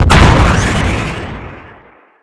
use_blast_ultra.wav